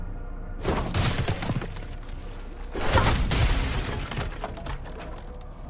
Breakable Object